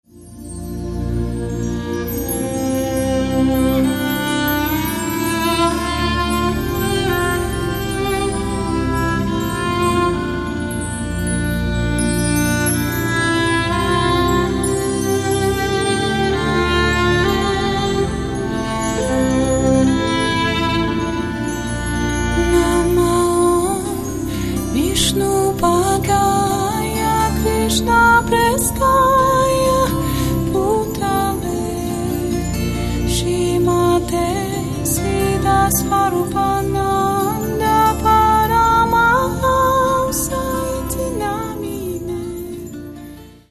Каталог -> Рок и альтернатива -> Лирический андеграунд